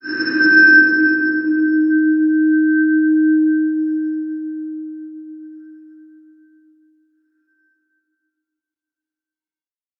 X_BasicBells-D#2-pp.wav